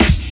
14 SNARE.wav